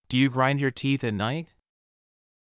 ﾄﾞｩ ﾕｰ ｸﾞﾗｲﾝﾄﾞ ﾕｱ ﾃｨｰｽ ｱｯ ﾅｲﾄ